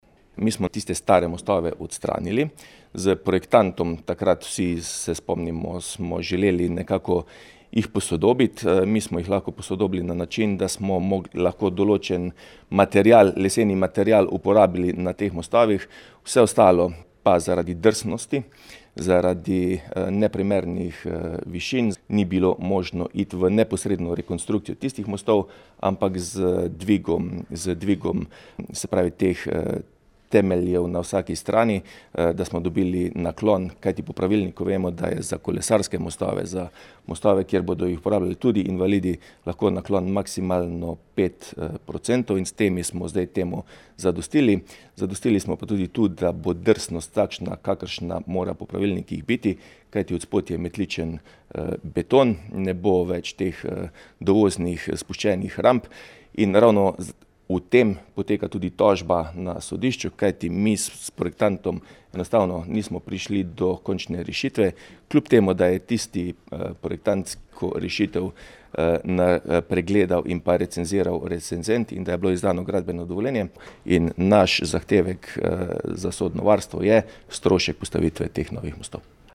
IZJAVA TILEN KLUGLER 2_1.mp3